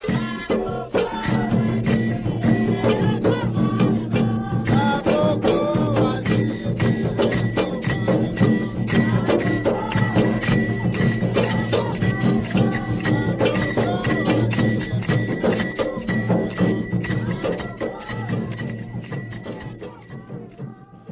Cada campana tiene un badajo que la hace sonar por sacudimiento.
Ensamble: Voces, batería de tambores Ilú, agogó
Característica: Canto en las ofrendas rituales de los cultos xangó de Recife.
Grupo Cultural: Afrobrasileño